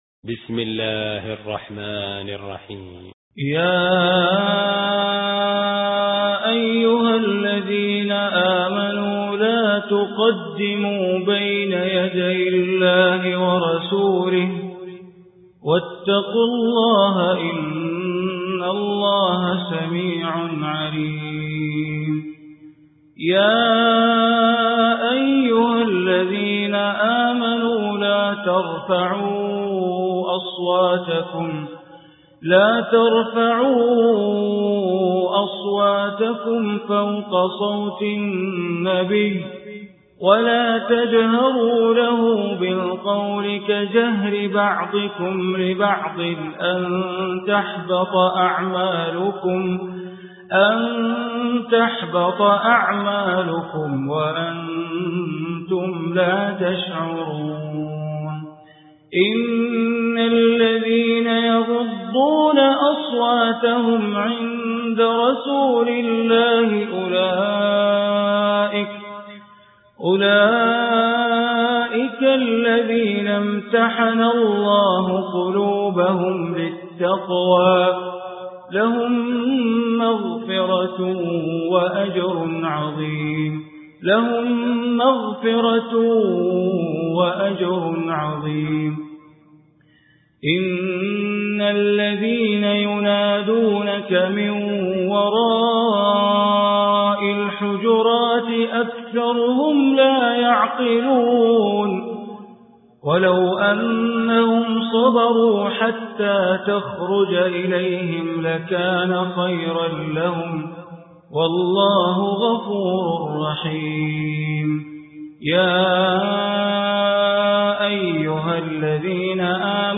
Surah Hujurat Recitation by Sheikh Bandar Baleela
Surah Hujurat, listen online mp3 tilawat / recitation in Arabic, recited by Imam e Kaaba Sheikh Bandar Baleela.